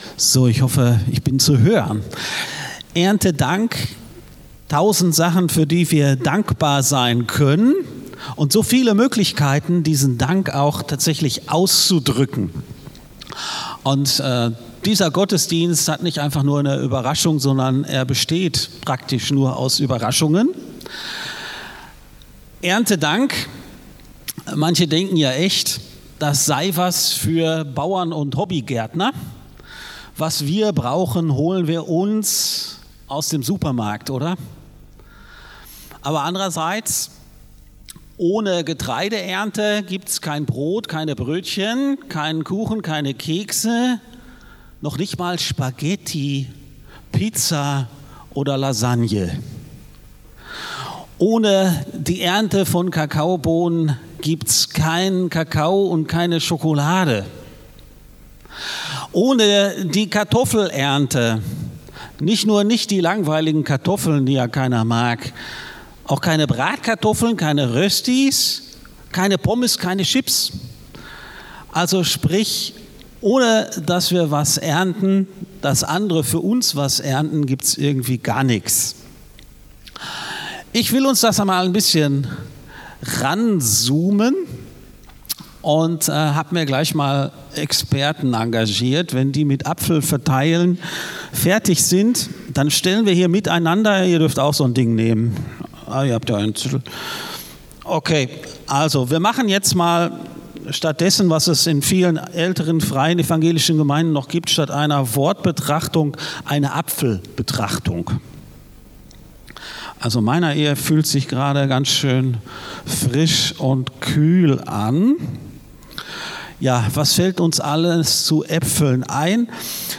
FeG Aschaffenburg - Predigt Podcast